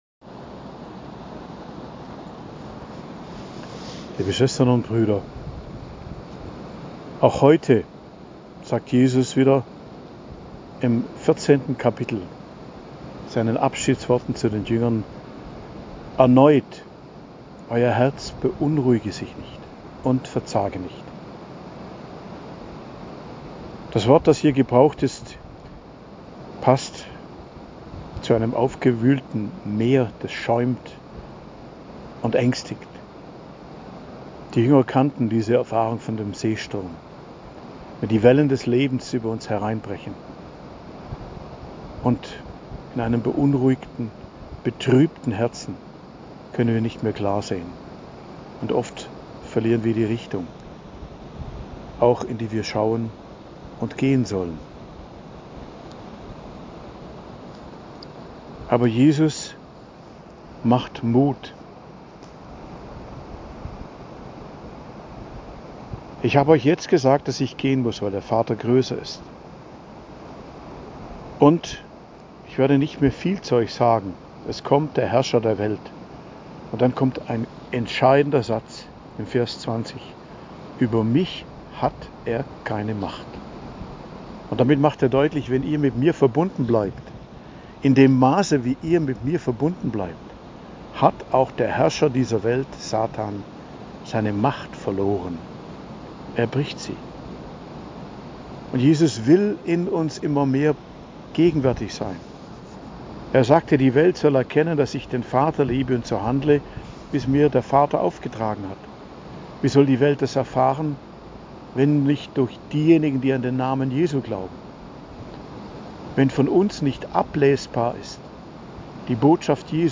Predigt am Dienstag der 5. Osterwoche, 30.04.2024